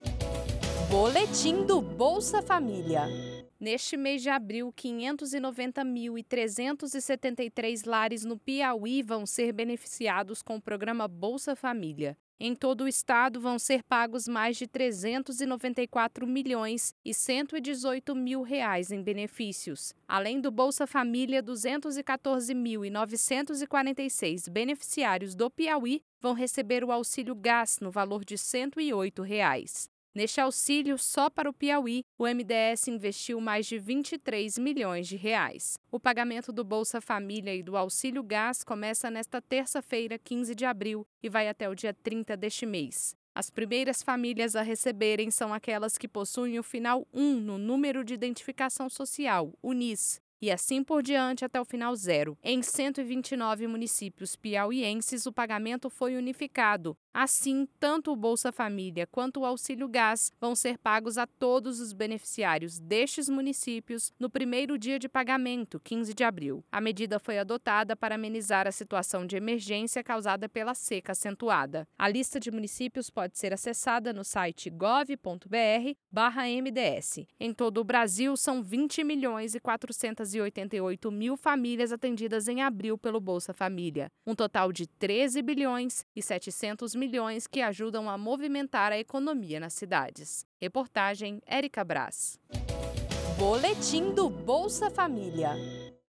Boletim do Bolsa Família